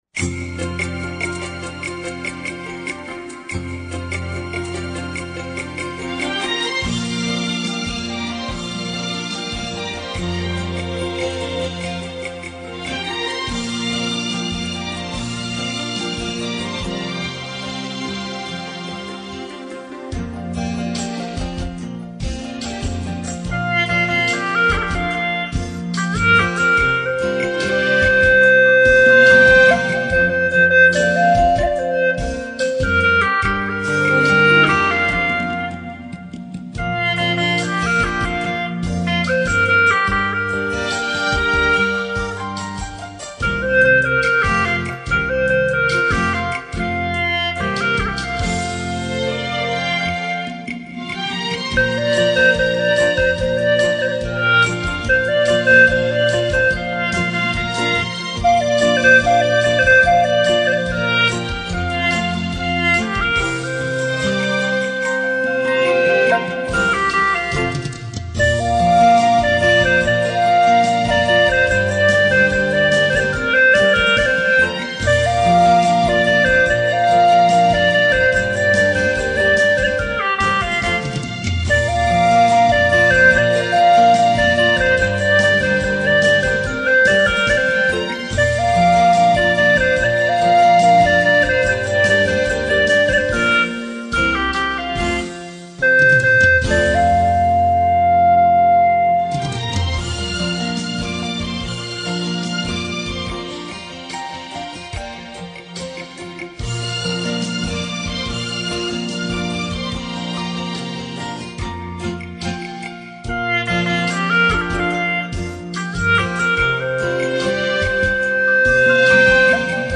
欣赏了，第一次听，吹的很深情啊。
不好意思了，我是躲在家里书房吹地。